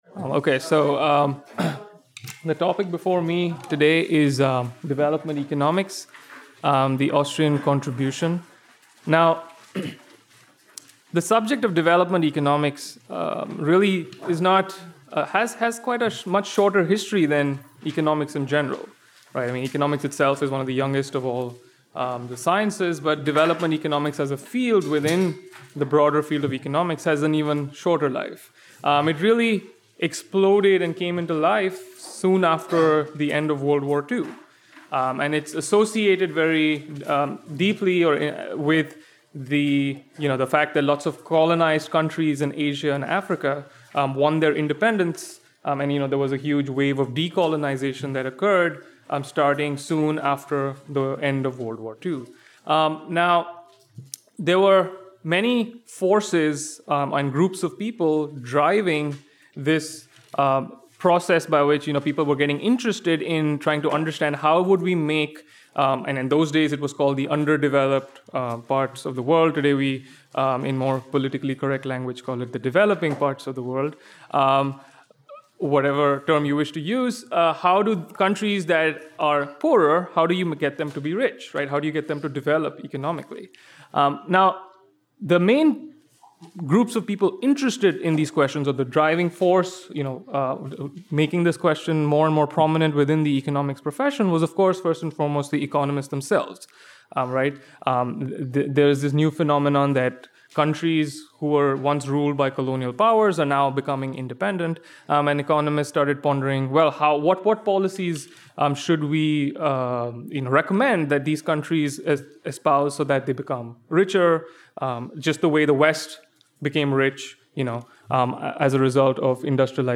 Recorded at the Mises Institute in Auburn, Alabama, on 28 July 2016.
Recorded at Mises University 2016.